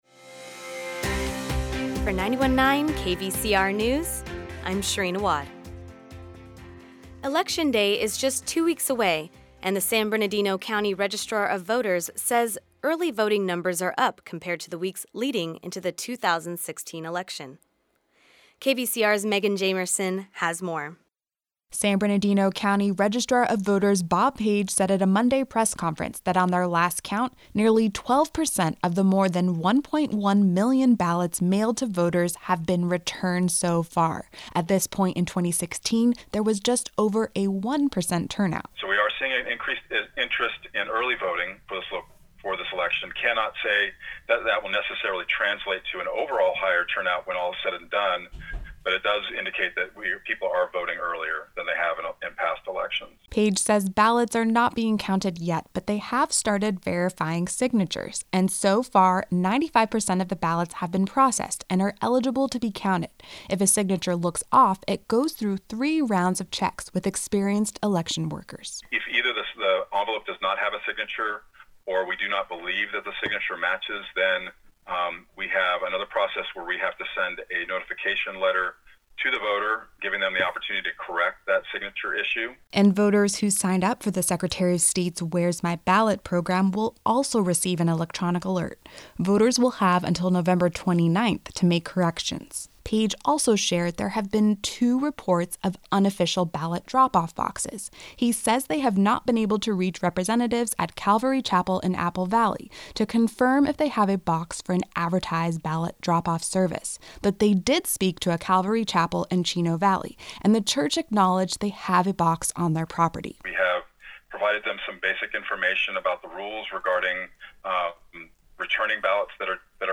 The Midday News Report